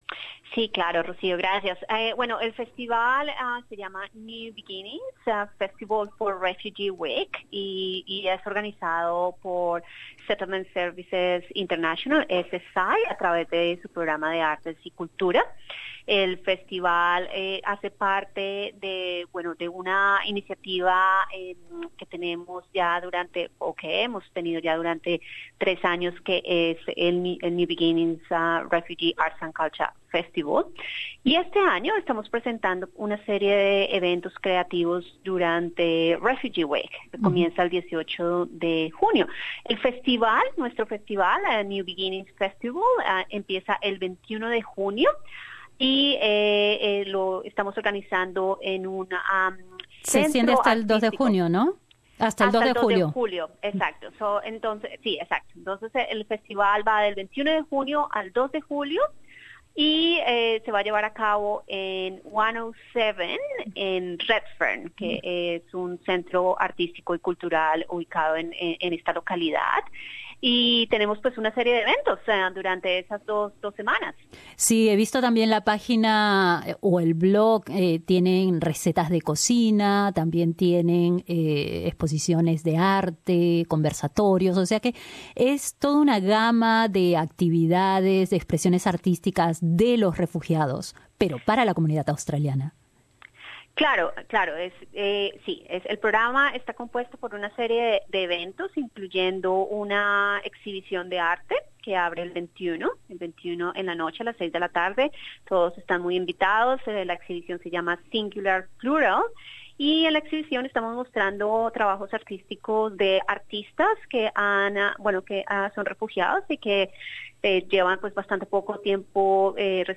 New beginnings, el festival de arte y cultura de los refugiados celebra el aporte de este colectivo de nuevos inmigrantes en Australia. Conversamos con una de las organizadoras de este evento